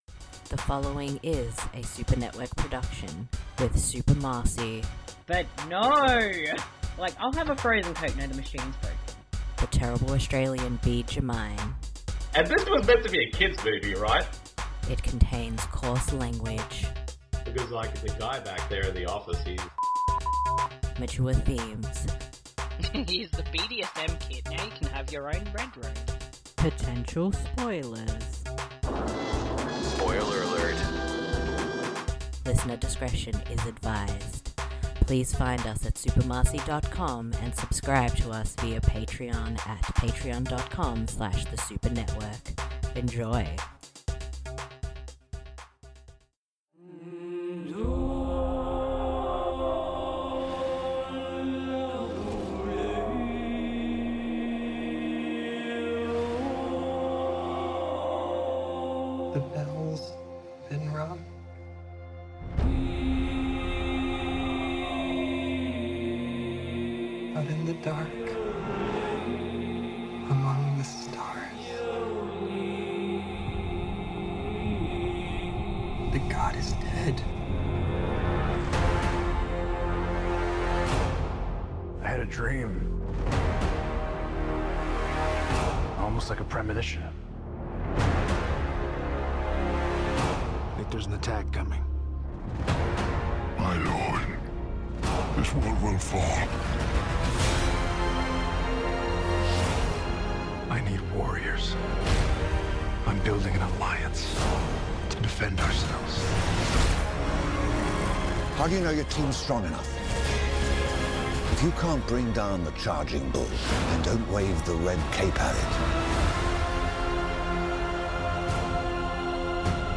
In this episode we discuss Zack Snyder's Justice League in detail and chat about other DC films worth a look.